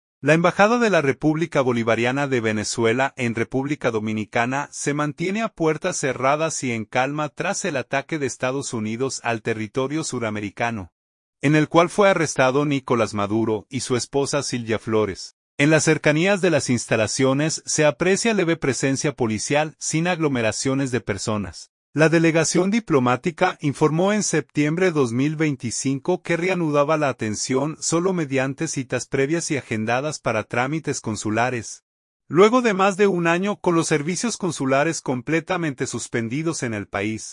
Ambiente en las afueras de la Embajada de Venezuela en RD; se mantiene a puertas cerradas